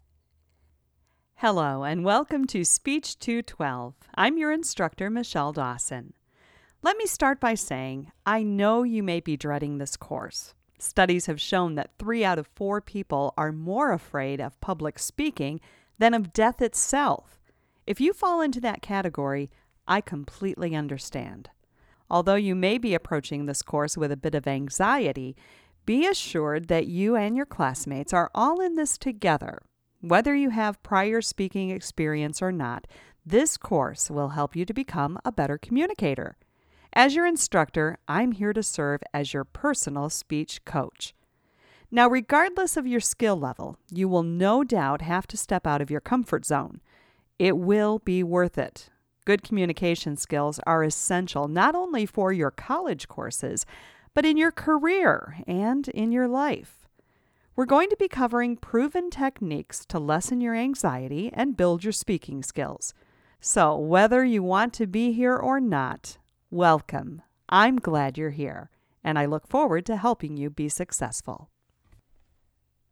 By posting a sincere, warm-hearted greeting, a professor can help online students feel welcomed.
As a professional announcer, I have the benefit of owning a home studio.